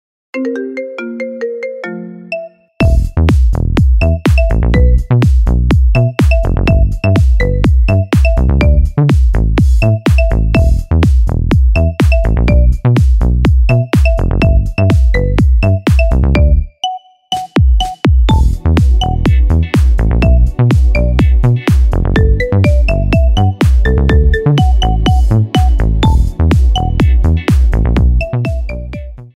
Kategorie Marimba Remix